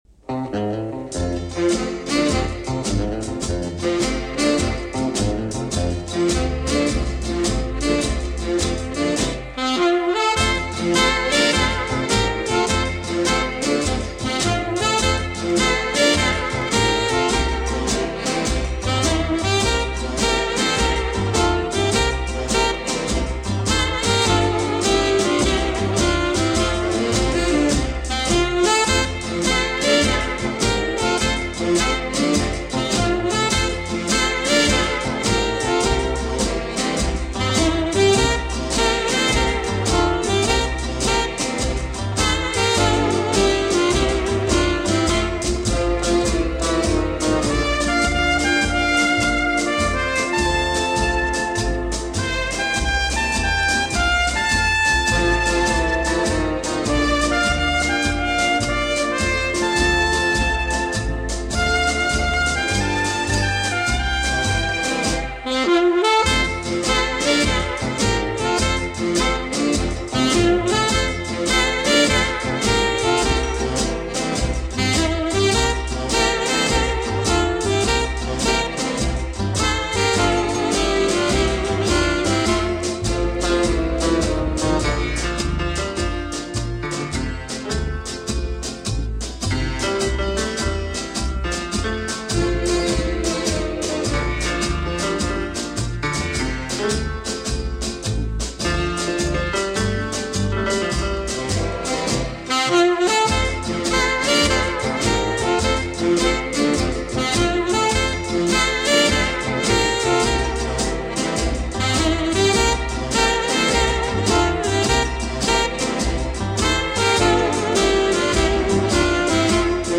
乐队的主奏并加入人声烘托柔和的气氛。